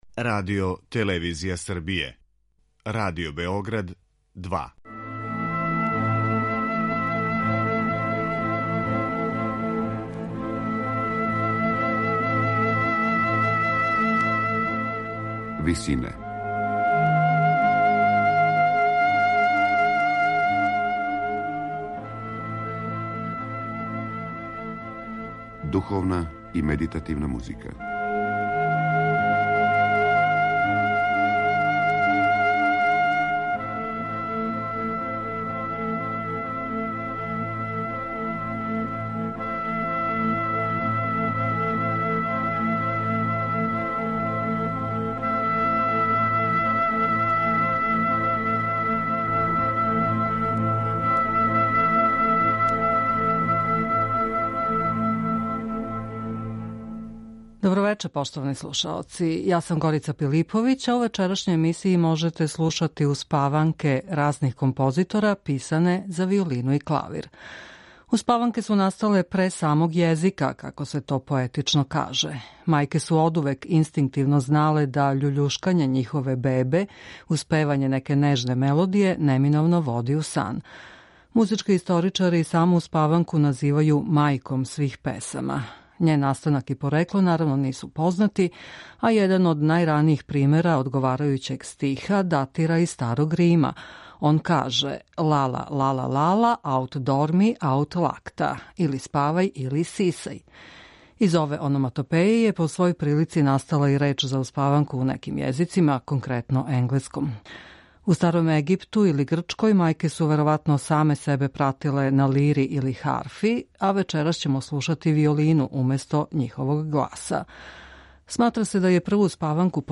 У вечерашњој емисији Висине можете слушати успаванке разних композитора писане за виолину и клавир.
Успаванке за виолину и клавир